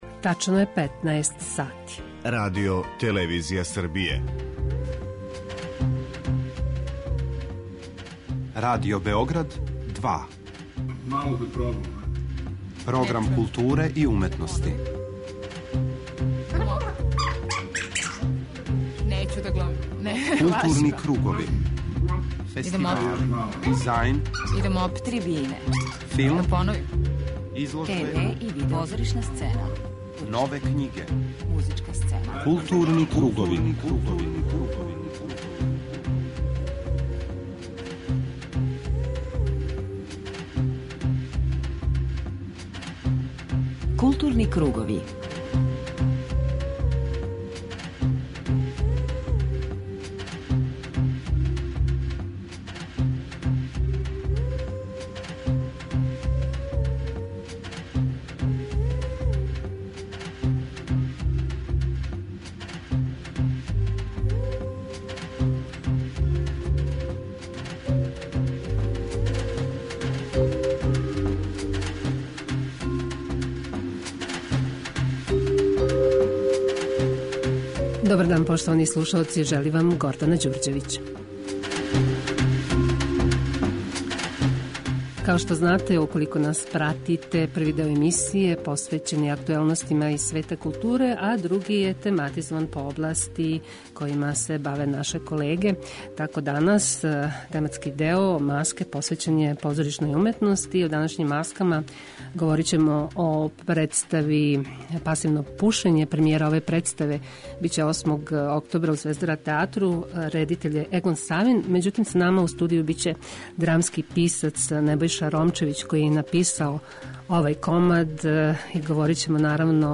преузми : 41.12 MB Културни кругови Autor: Група аутора Централна културно-уметничка емисија Радио Београда 2.